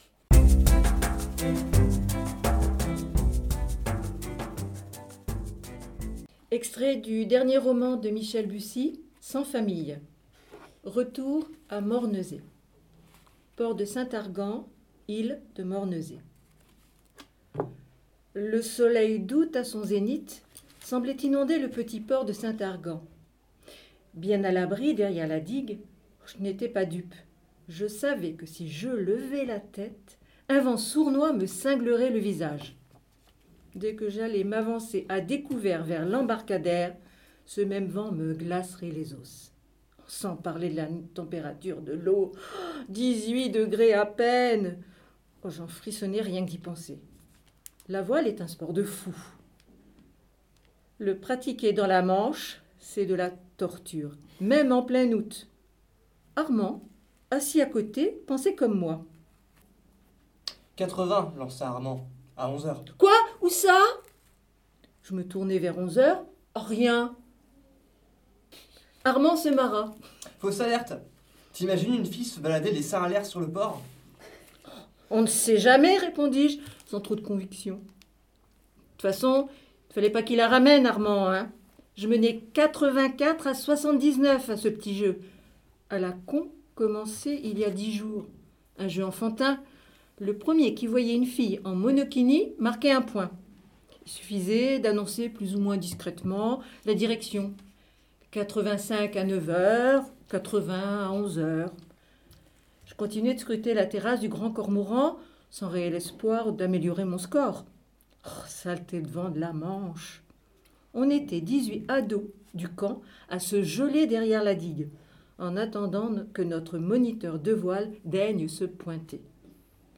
Les hauts parleurs de l’association « Lire à Saint-Lô » prêtent leurs voix sur MDR! Cette fois-ci, ils nous lisent les extraits des livres suivants :  « Sans feuille » de Michel Bussi et « Canisy » de Jean Follain